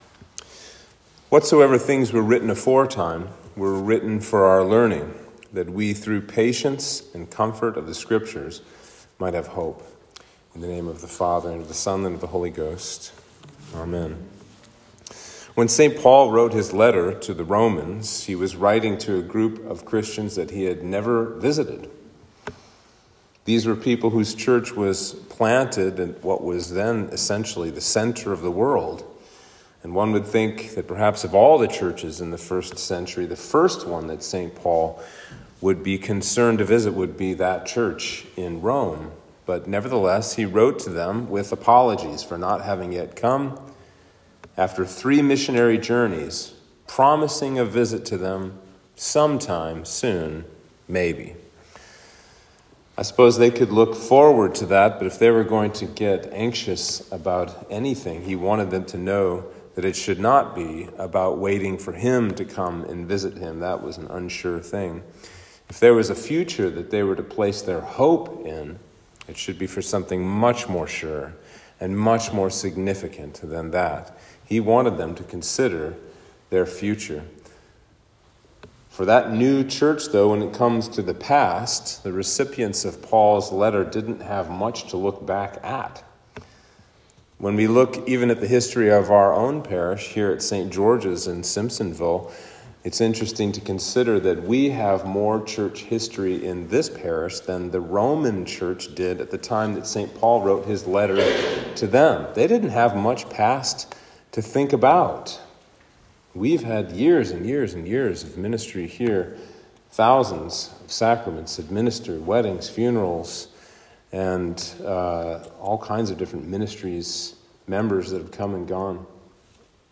Sermon for Advent 2